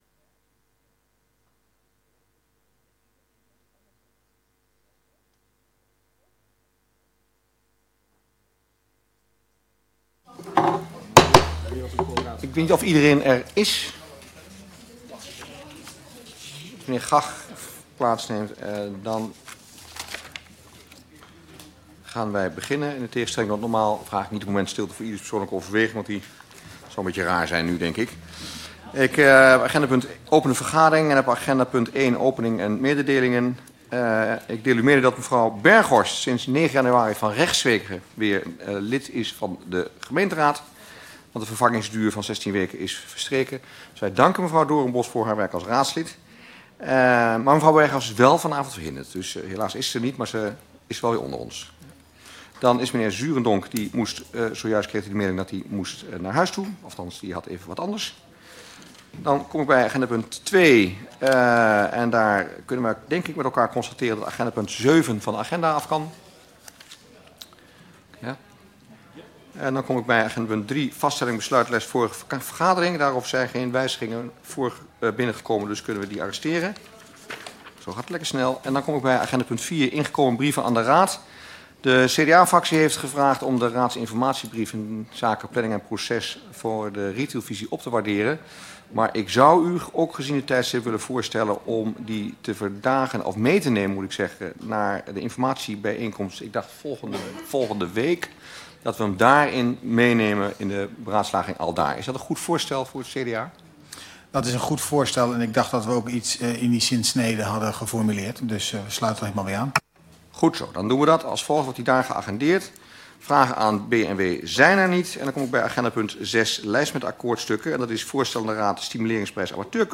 Raadsvergadering 16 januari 2020 20:30:00, Gemeente Dronten
Locatie: Raadzaal